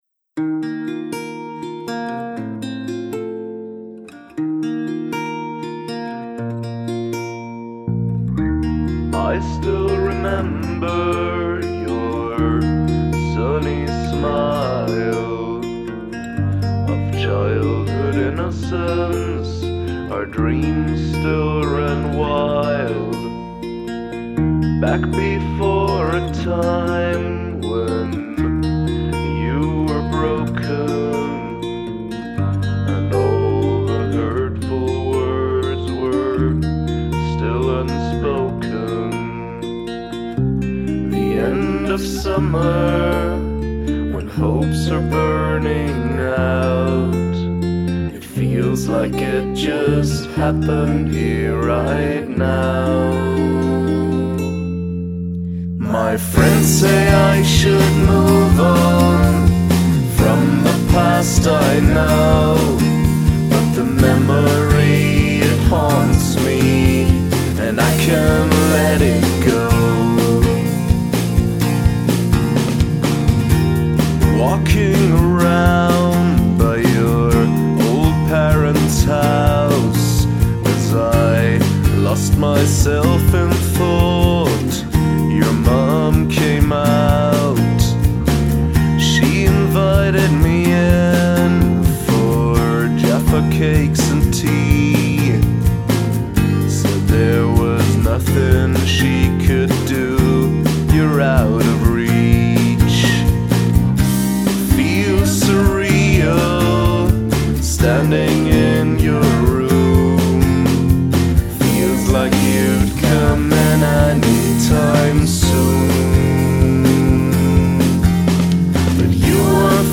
Intro vocals feel buried, as if sung through a blanket.